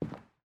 Footsteps / Carpet
Carpet-09.wav